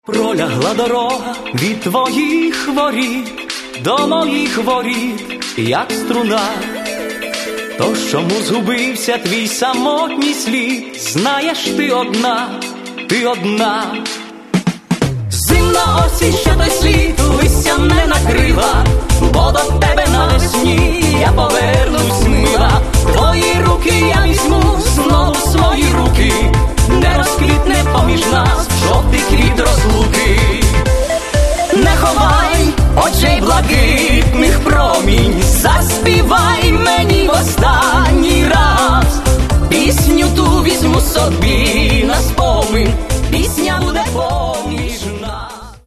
в исполнении современных поп-артистов.